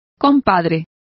Complete with pronunciation of the translation of buddies.